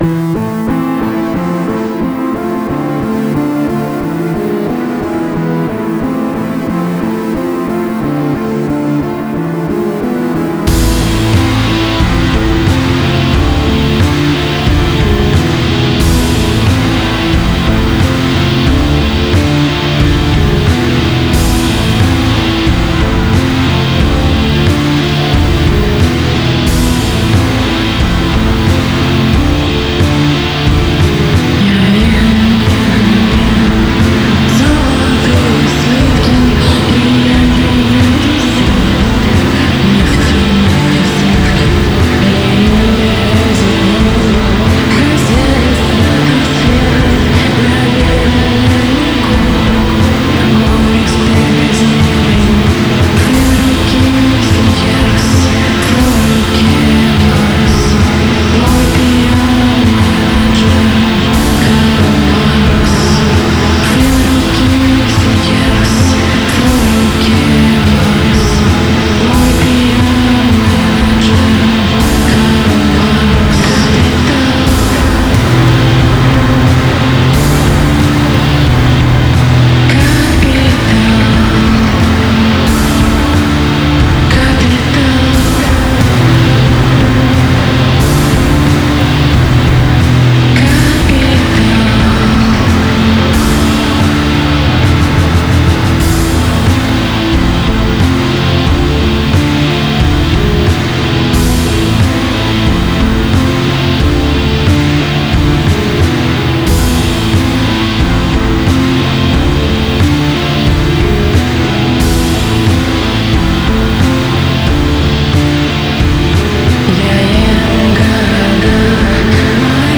scary song